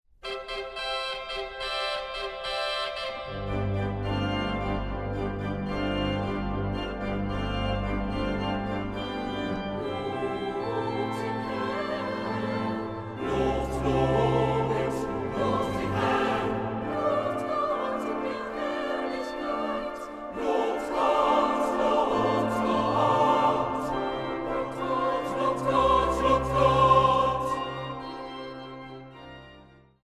Orgel
Die Aufnahmen fanden in der Kreuzkirche in Dresden statt.